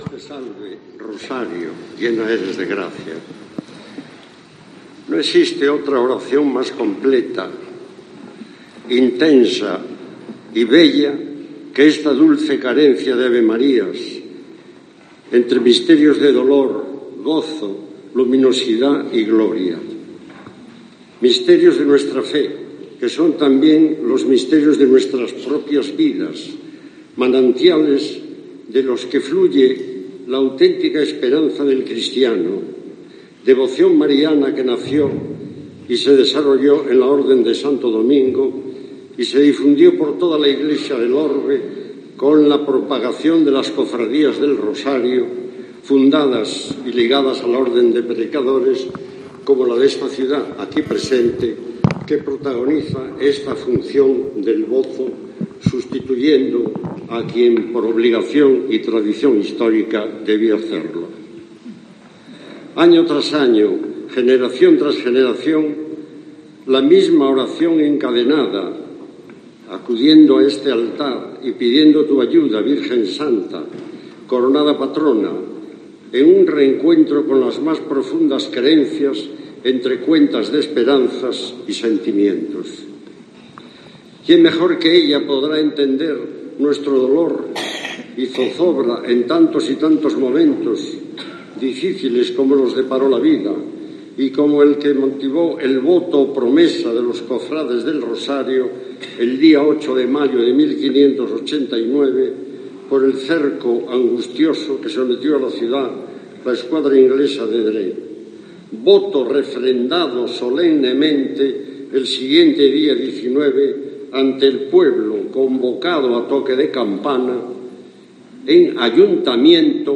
Discurso José Manuel Liaño Flores en la Función del Voto 2018
Función del Voto 2018. Oferente: José Manuel Liaño Flores